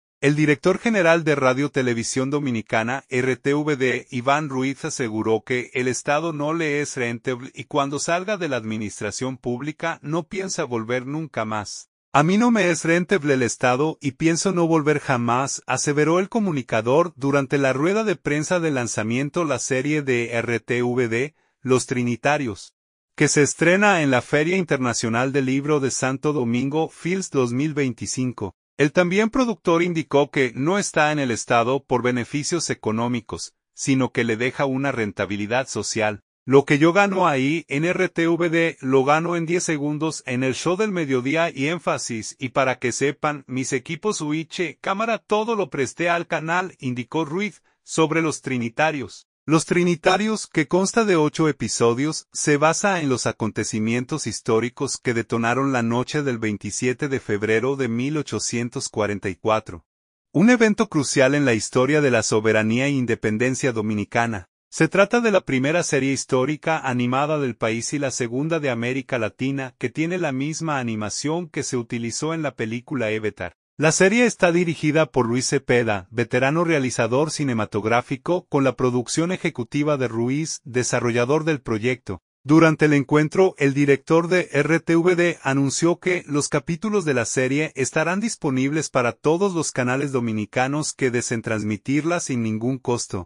El director de RTVD ofreció estas declaraciones en la rueda de prensa del lanzamiento la serie de “Los Trinitarios”